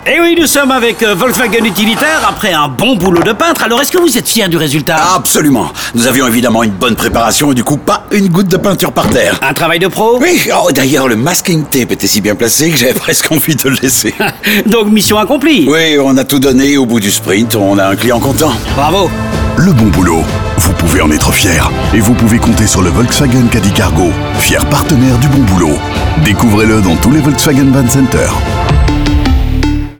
Le volet radio se focalise aussi sur les professionnels, dans des séries de 3 spots radio où ils parlent fièrement de leur boulot comme des sportifs parlent fièrement de leurs prestations.